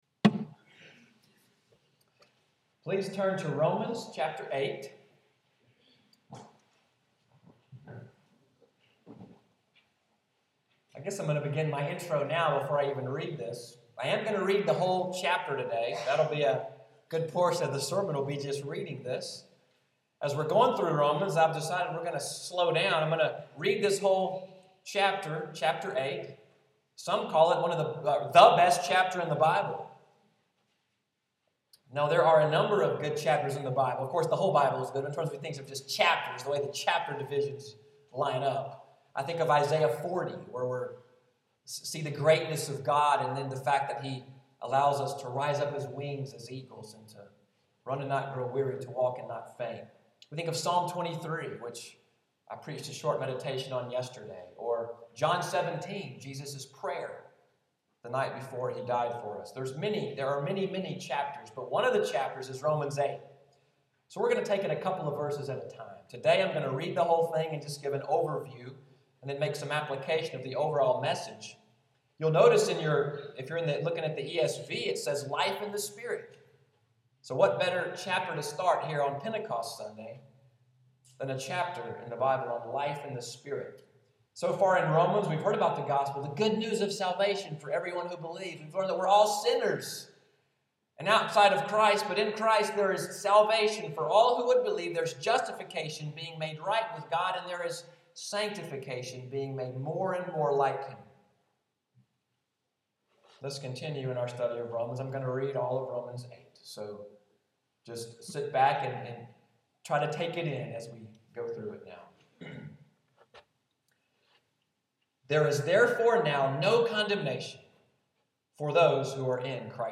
Sunday’s sermon, “If God Is for Us…“, an introduction to Romans 8, May 24, 2015